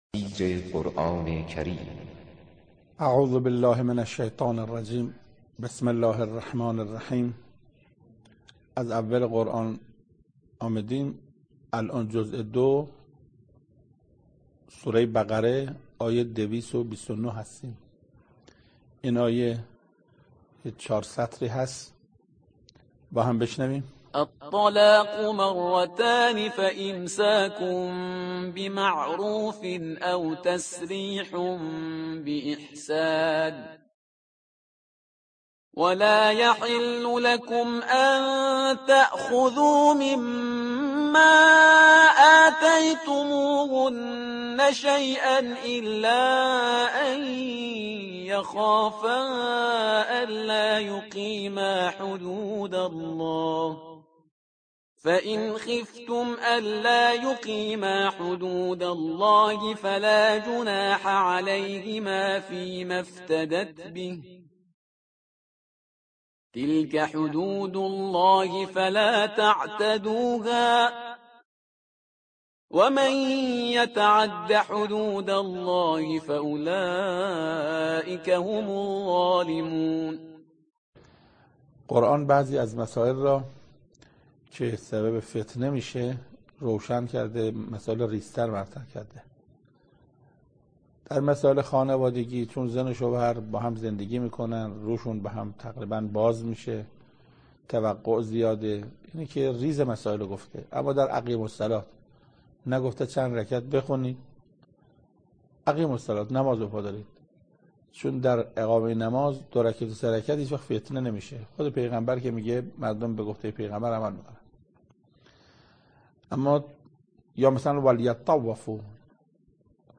تفسیر دویست و بیست و نهمین آیه از سوره مبارکه بقره توسط حجت الاسلام استاد محسن قرائتی به مدت 17 دقیقه